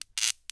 auto_idle_sparks2.wav